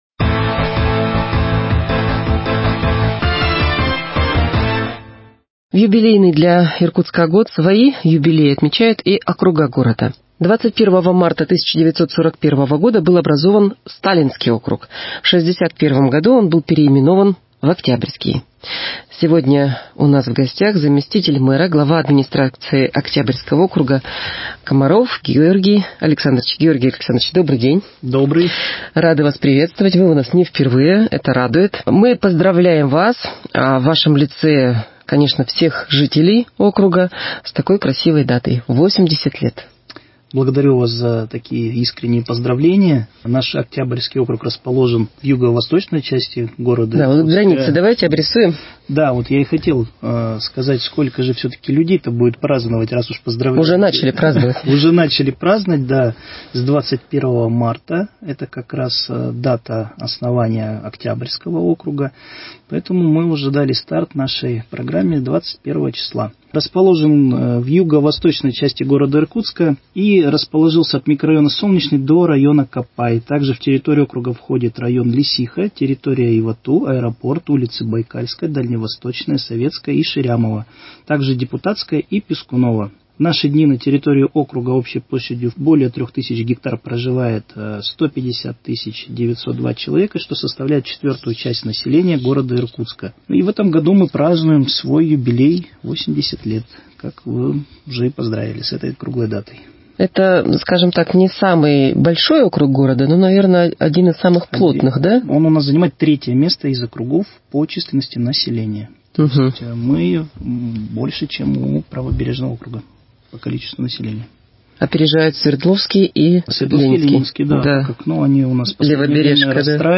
Актуальное интервью: Георгий Комаров о 80-летии Октябрьского округа 29.03.2021
В гостях у "Подкаст"а заместитель мэра – председатель комитета управлением Октябрьского округа Георгий Комаров.